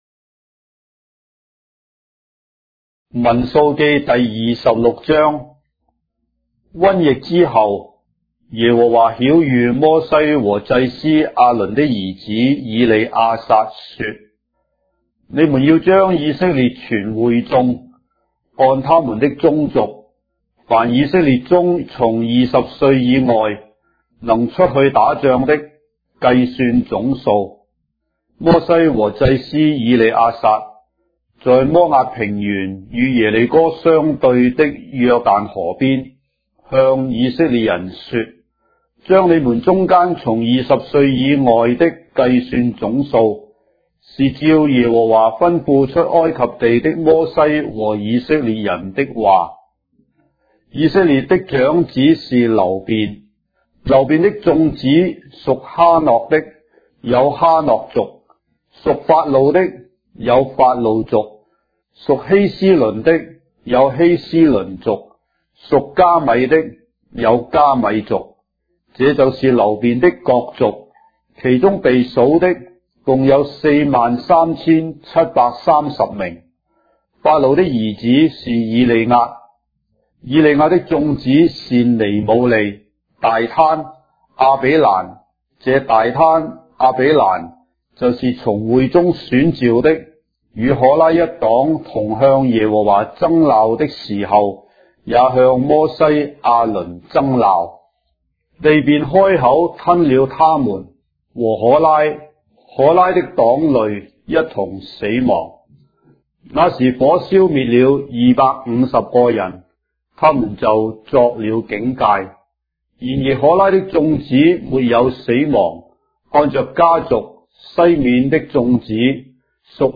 章的聖經在中國的語言，音頻旁白- Numbers, chapter 26 of the Holy Bible in Traditional Chinese